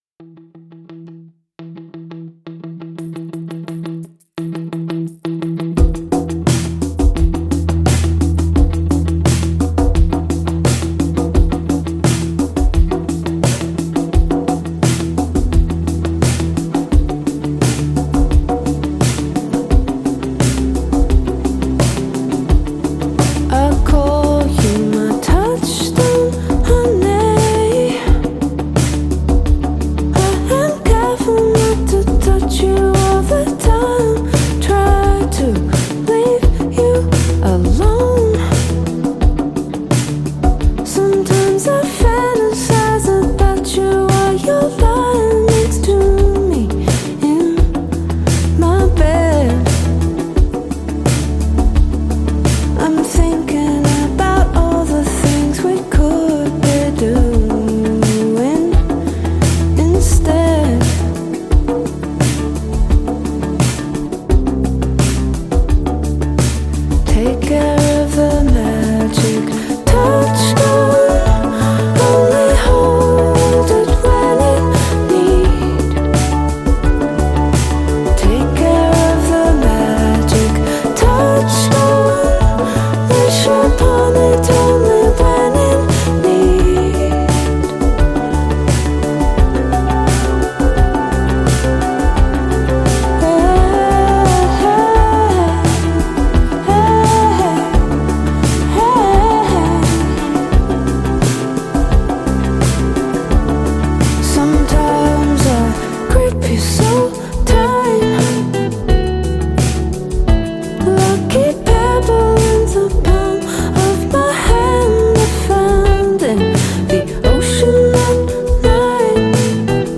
# Alternative Rock